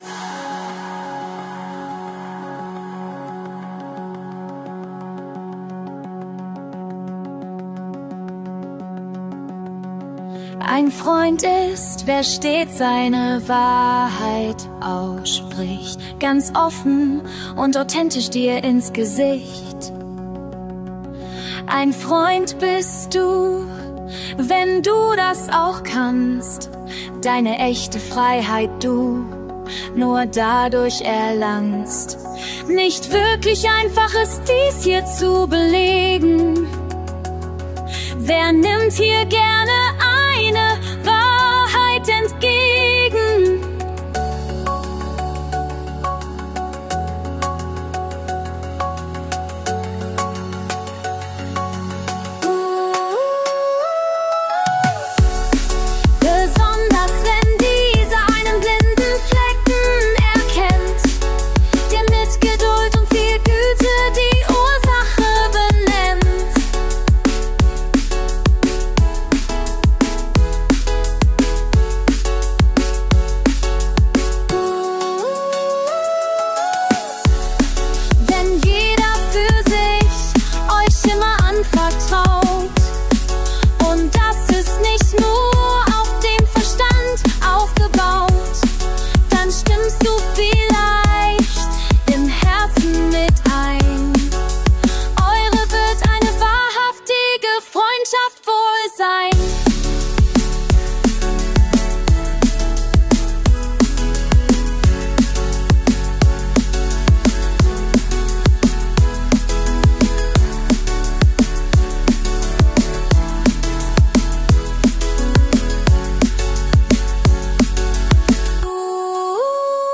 Liquid DNB